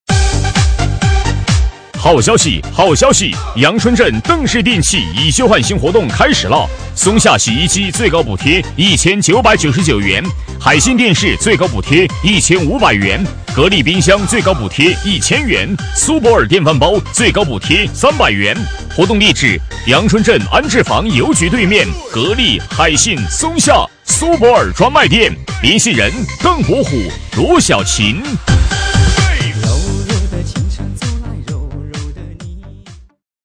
【男8号促销】邓氏电器
【男8号促销】邓氏电器.mp3